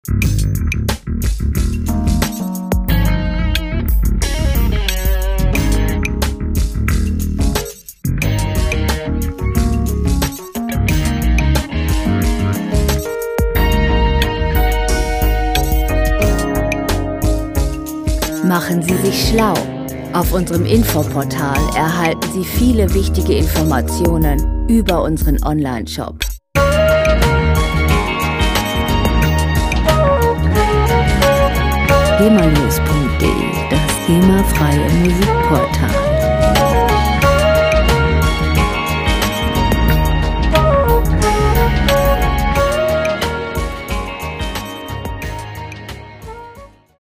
• Nu Jazz